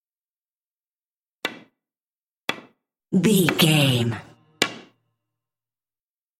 Billards stick hit ball
Sound Effects
hard